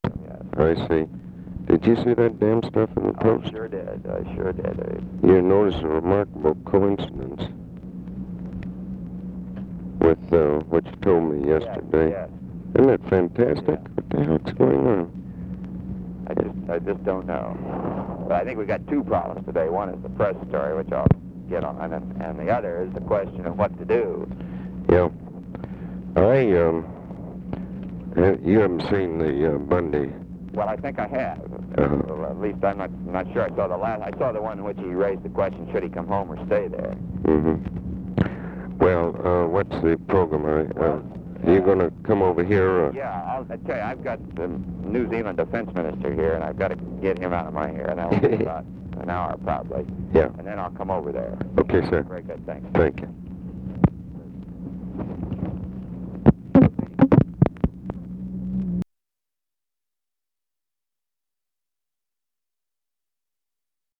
Conversation with ABE FORTAS
Secret White House Tapes